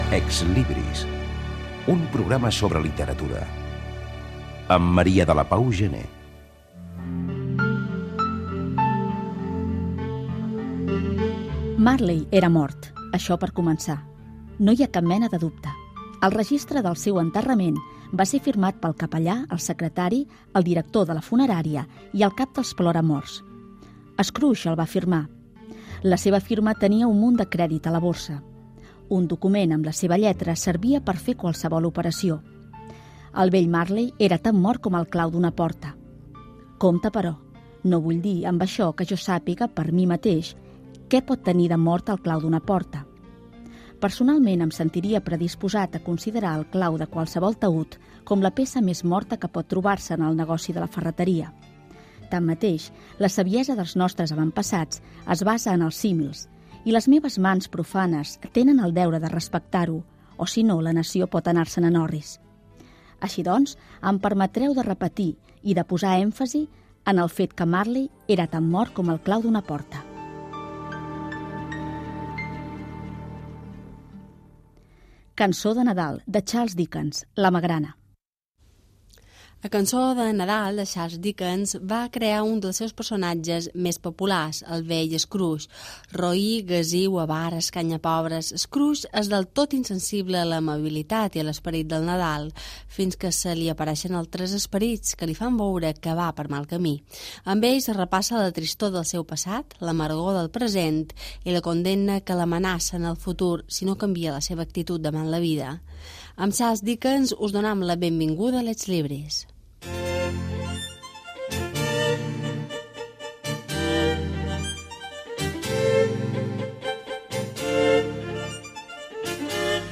59c72e8be8323ded80d8b30a70b788e2291fbd2b.mp3 Títol Catalunya Ràdio Emissora Catalunya Ràdio Cadena Catalunya Ràdio Titularitat Pública nacional Nom programa Ex-Libris Descripció Indicatiu del programa, fragment de "La cançó de Nadal" de Charles Dickens.
Perfil biogràfic de l'autor i adaptació radiofònica de l'obra.